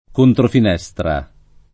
[ kontrofin $S tra ]